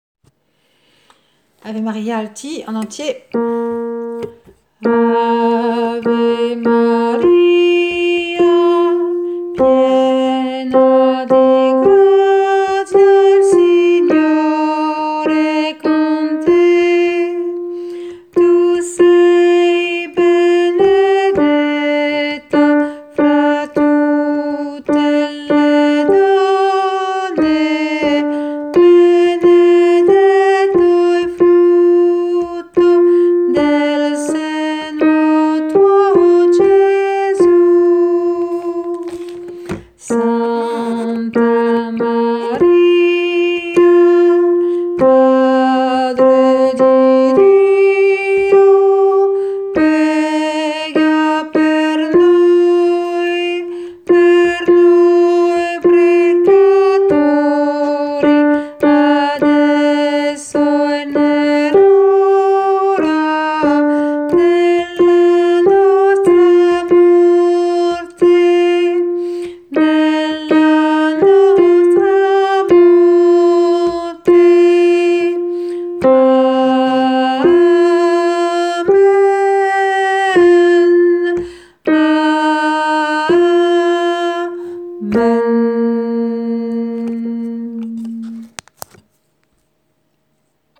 Voix Alto
ave-maria-boix-alto-en-entier.mp3